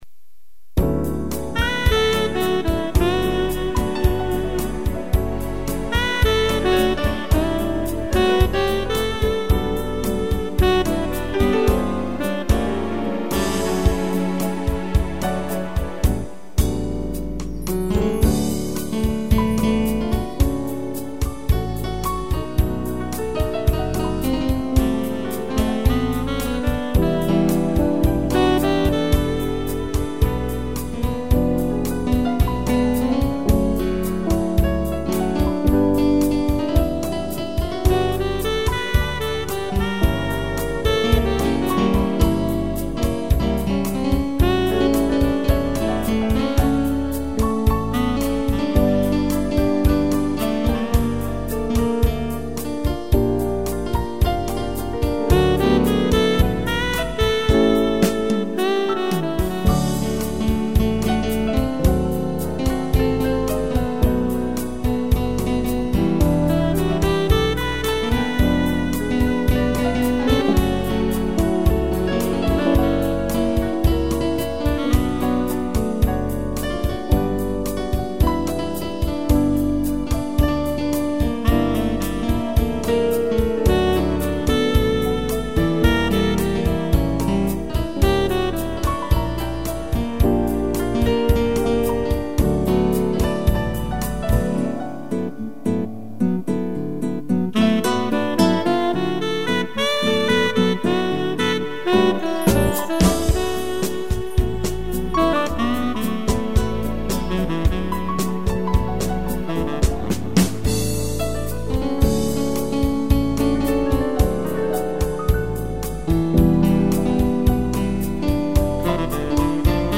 piano e sax
instrumental